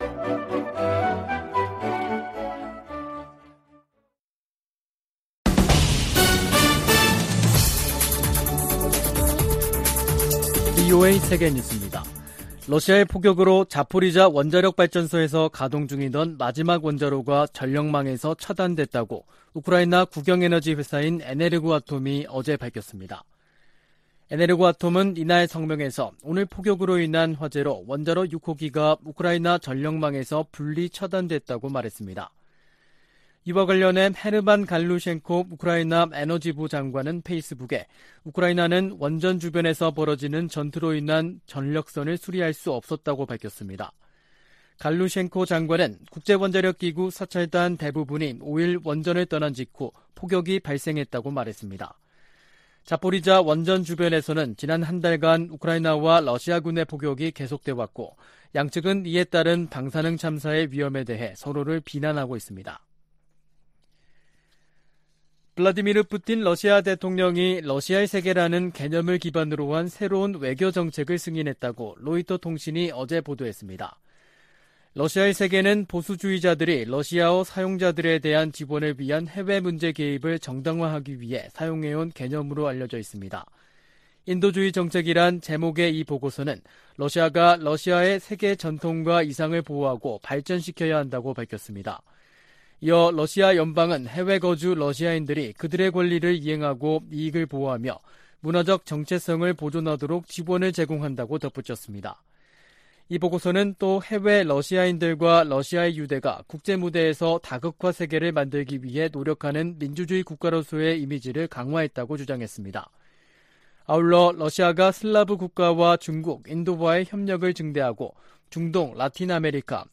VOA 한국어 간판 뉴스 프로그램 '뉴스 투데이', 2022년 9월 6일 3부 방송입니다. 미국과 한국, 일본 외교수장들은 북한이 7차 핵실험을 감행할 경우 이전과는 다른 대응을 예고했습니다. 미한일 협력이 강조되는 가운데, 한일관계에는 여전히 온도차가 있다고 미국 전문가들이 지적했습니다. 봄 가뭄과 ‘코로나’ 이중고를 겪고 있는 북한 경제에 “개혁・개방이 살 길”이라고 미국 전문가들이 진단했습니다.